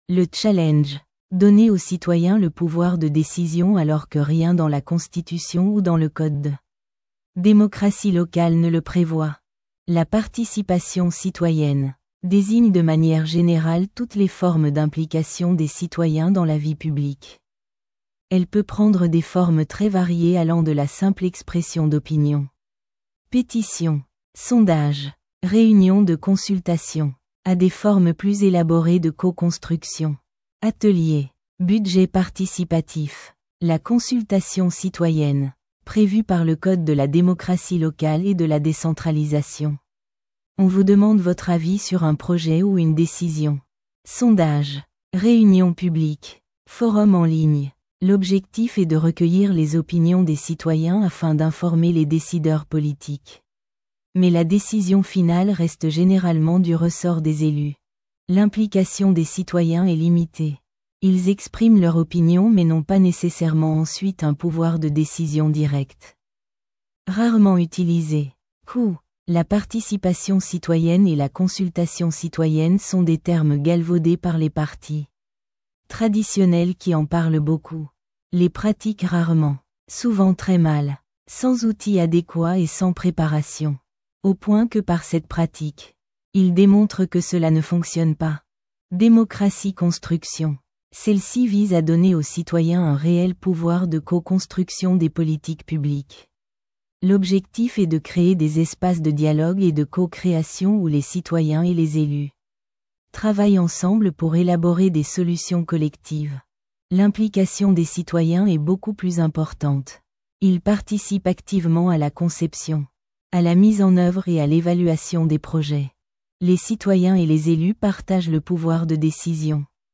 democratieaudiobook.mp3